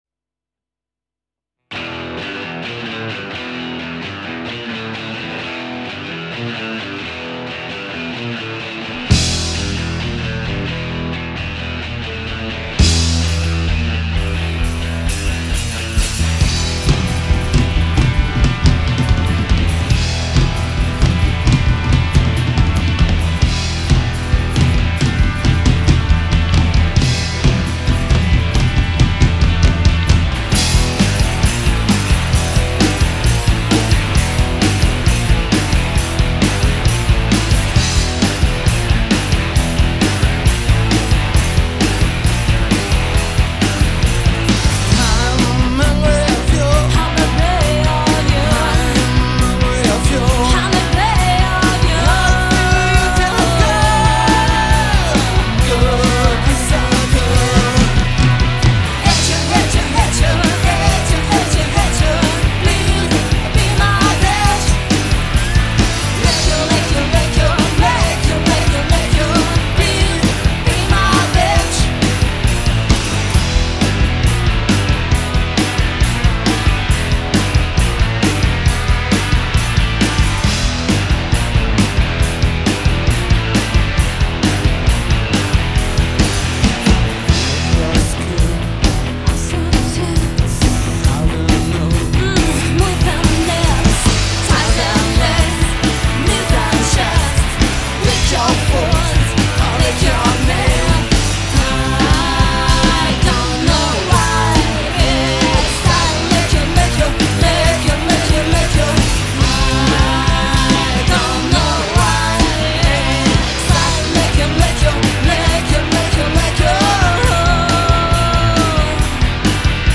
chant lead
basse
batterie
guitares